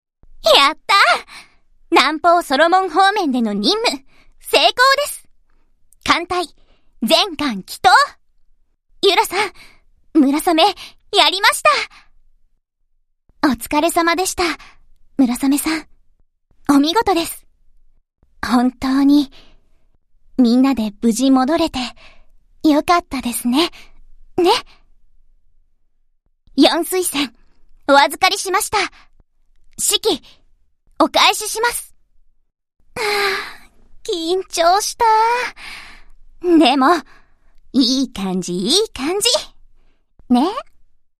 Here are the voice lines played upon completion of certain quests.